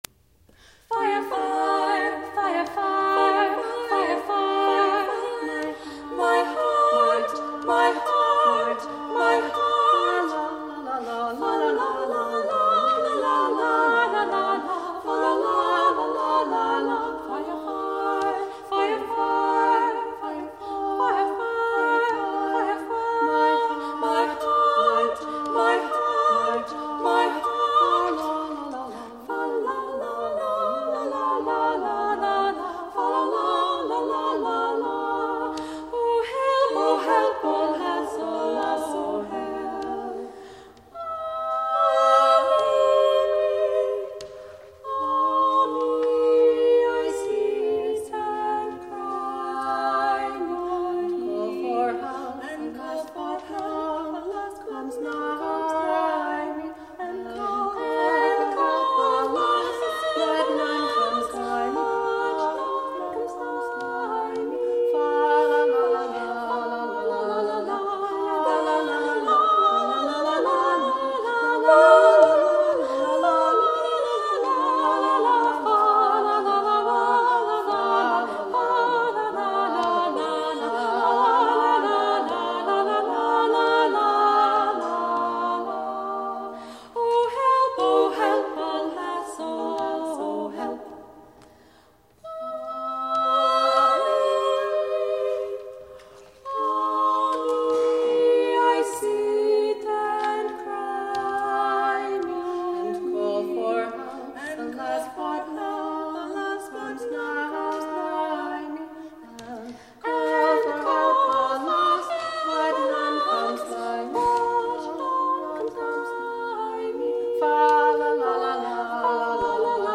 Songs and Clips - 2nd Half - Vocal
TRIO Section: